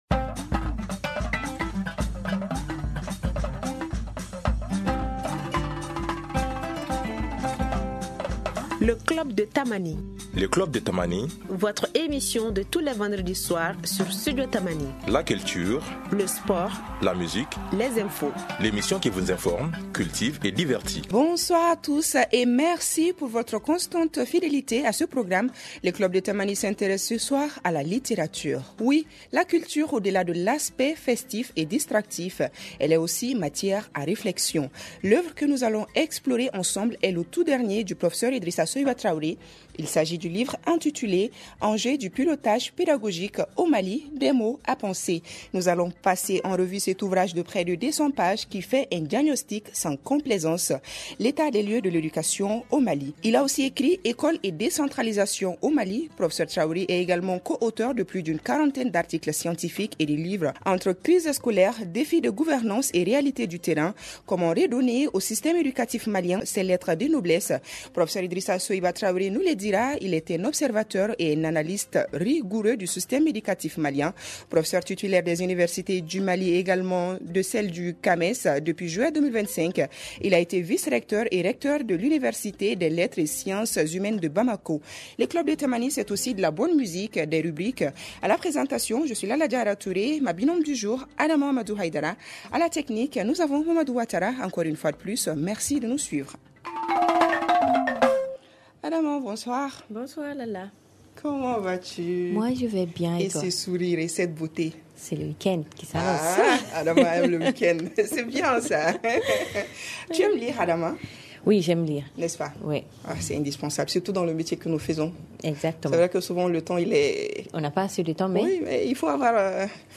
Entre décentralisation et défis pédagogiques, rencontre avec un expert au chevet de l’éducation.